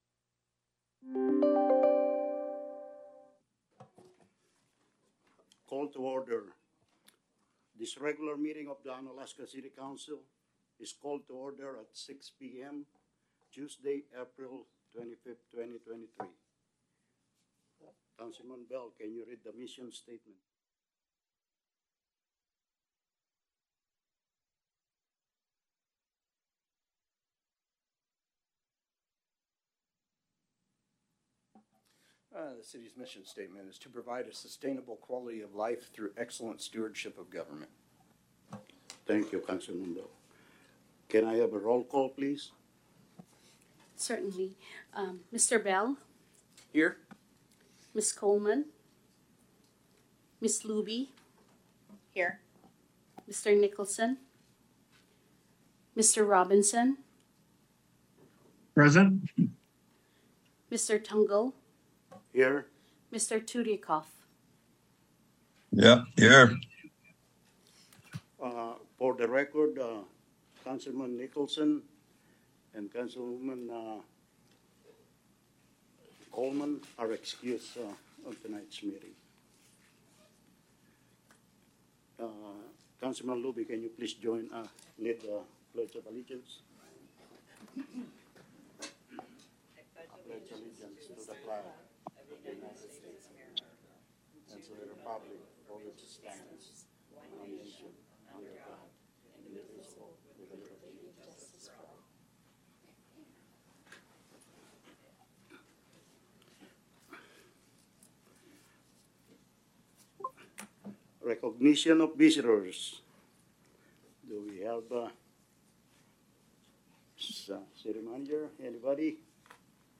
City Council Meeting - April 25, 2023 | City of Unalaska - International Port of Dutch Harbor
In person at City Hall (43 Raven Way)